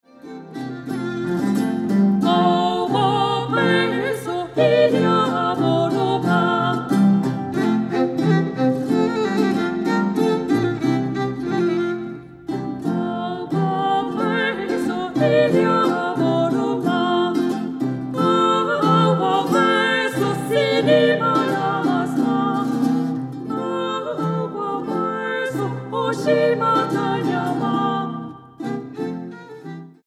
soprano, flauta, percusiones
violín, violín piccolo
guitarra barroca, laúd, mandolina, charango, viola de gamba